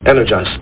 Sound Effects for Windows
energize.mp3